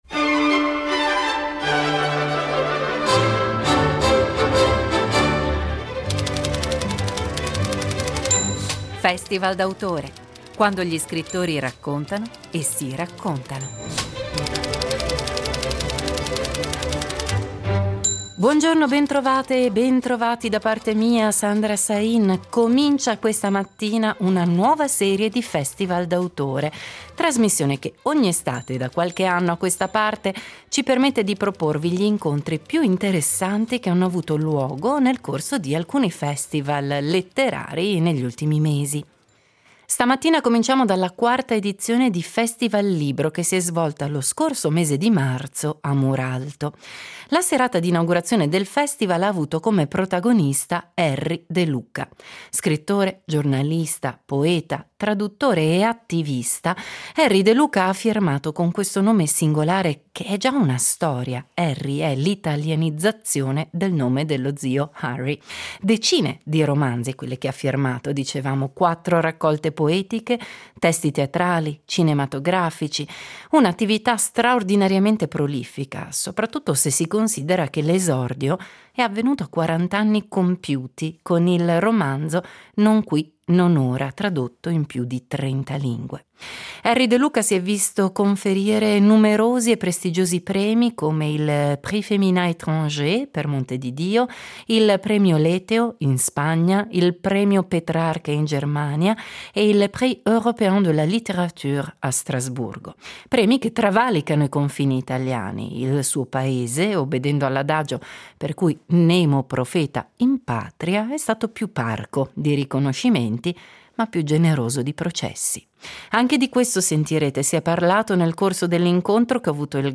Erri De Luca al FestivalLibro Muralto
Scrittore, giornalista, poeta, traduttore e attivista, Erri De Luca ha inaugurato la 4° edizione del FestivalLibro di Muralto. Decine di romanzi, raccolte poetiche, traduzioni, testi teatrali, cinematografici… il mondo letterario di De Luca è vasto ed eterogeneo.